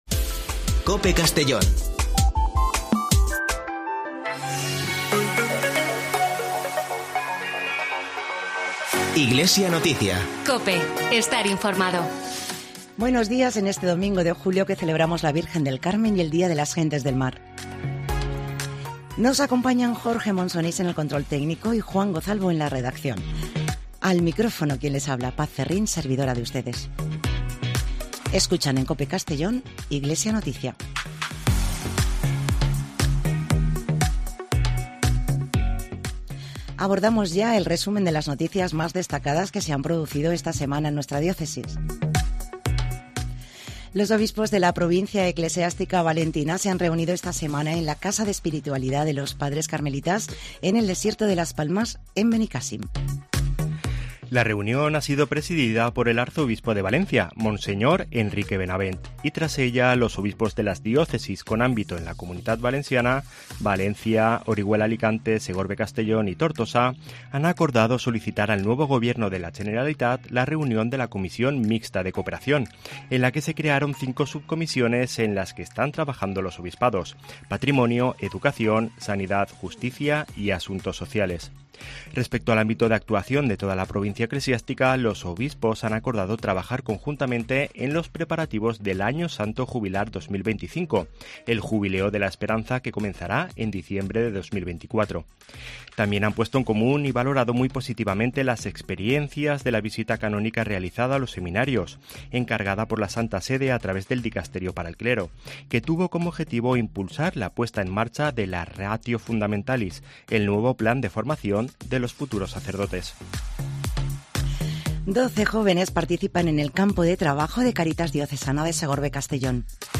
Espacio informativo de la Diócesis de Segorbe-Castellón